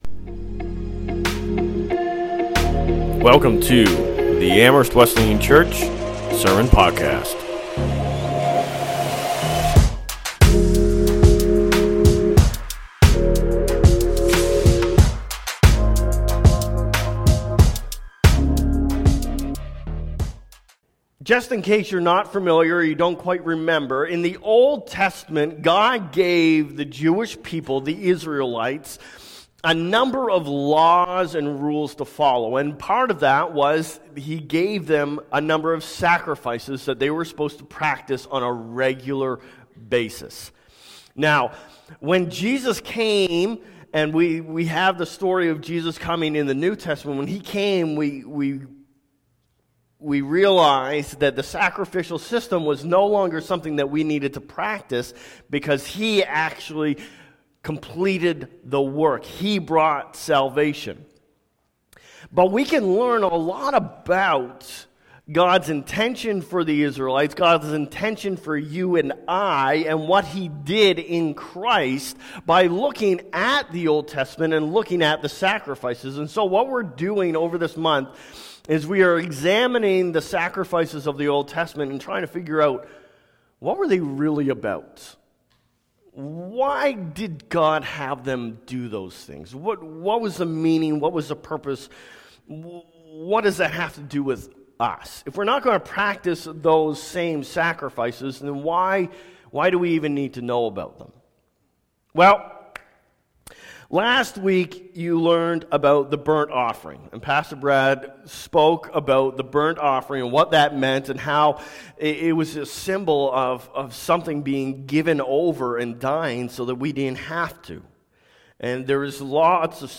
Sermons | Amherst Wesleyan Church